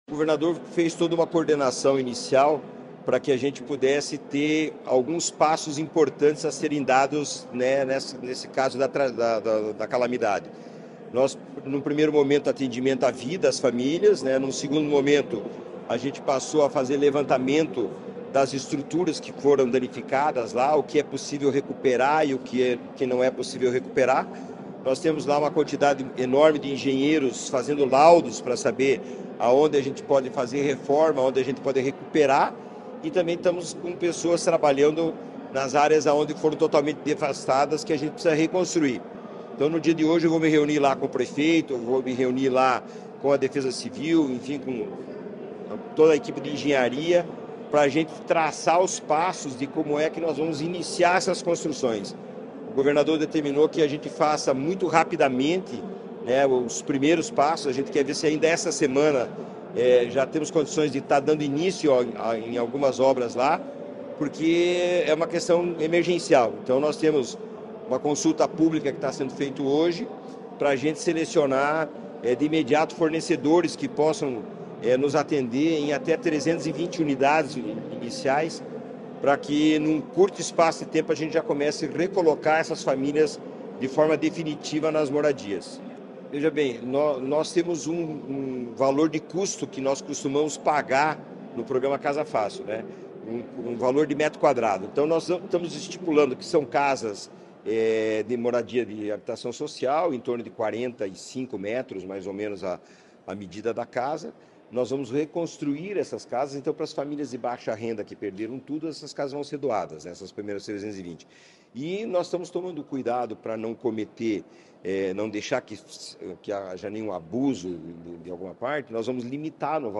Sonora do presidente da Cohapar, Jorge Lange, sobre a construção emergencial de 320 casas em Rio Bonito do Iguaçu